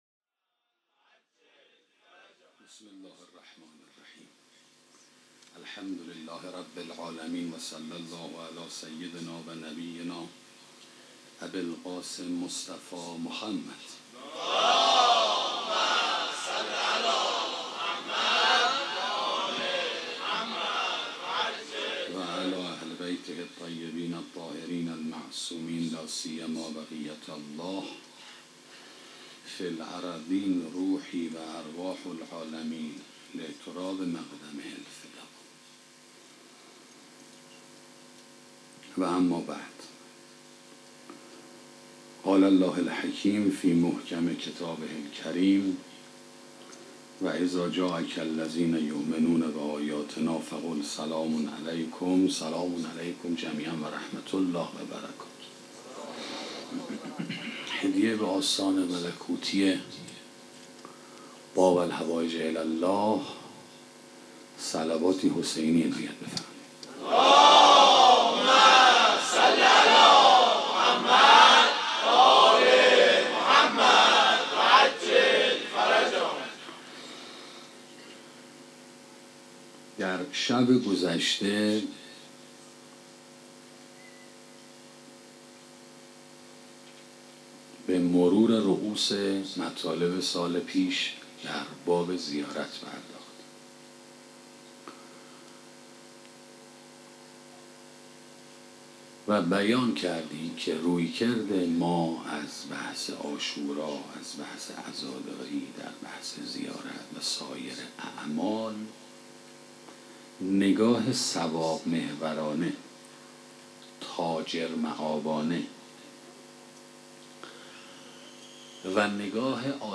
سخنرانی زیارت اخوان - موسسه مودت
sokhanrani-shabe-7.mp3